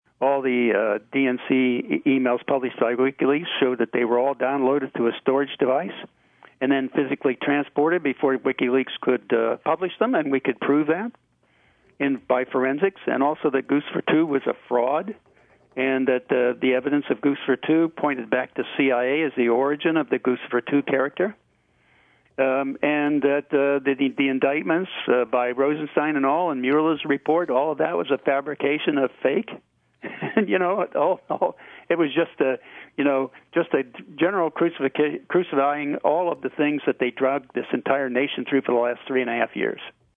In-Depth Interview: Former NSA Technical Director Bill Binney Has More Proof Debunking Russiagate